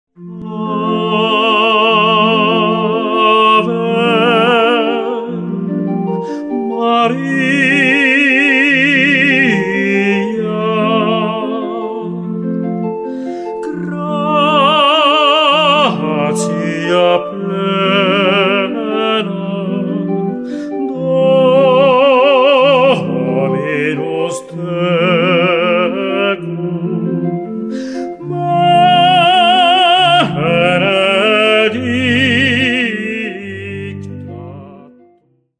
Live-Mitschnitte: